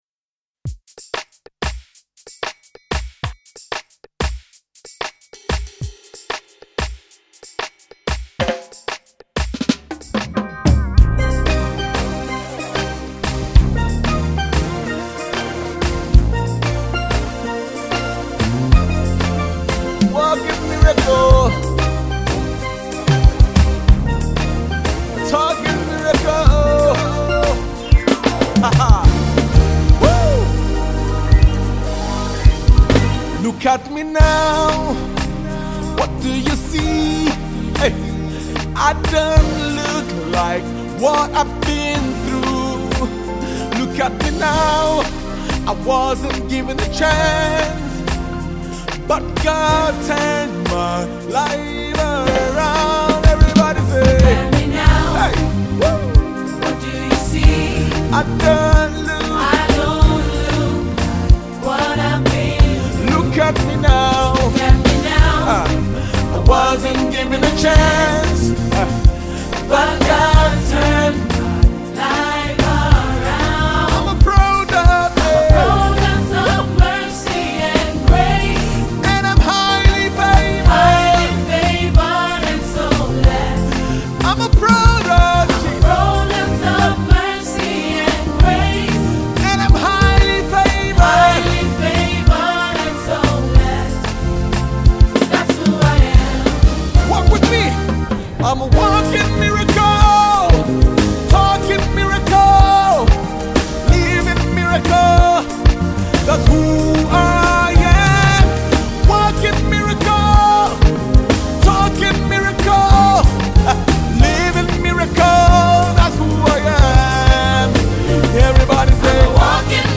powerful and and soul lifting single